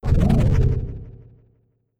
SFX
midboss hurt.wav